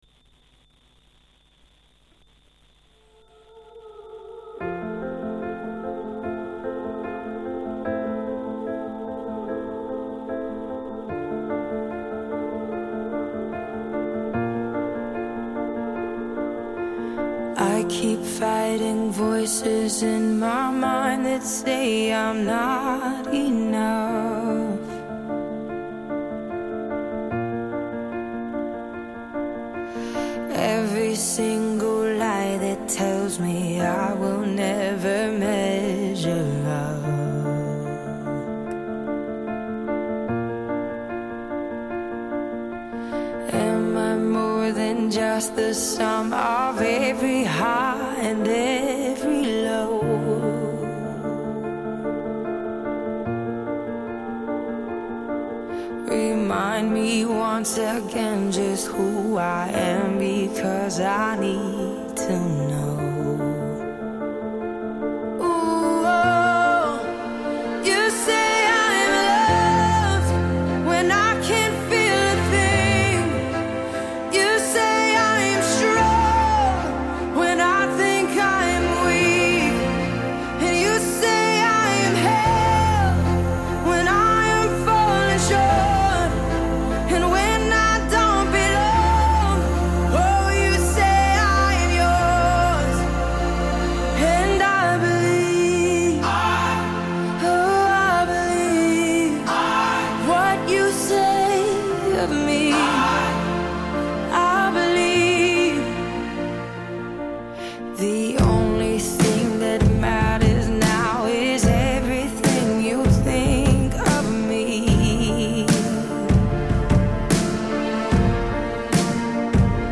Christian music to cheer your day or just bring a point of difference